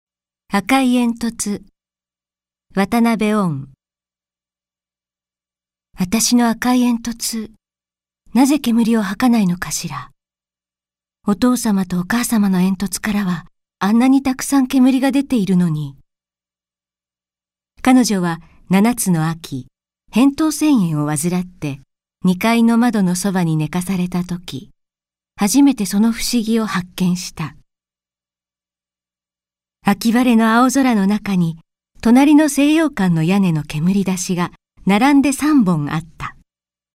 SEKAIMEN - 朗読ＣＤ 朗読街道126「赤い煙突・嘘」渡辺温 試聴あり
朗読街道は作品の価値を損なうことなくノーカットで朗読しています。